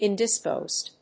ìn・dis・pósed
音節in･dis･posed発音記号・読み方ɪ̀ndɪspóʊzd
indisposed.mp3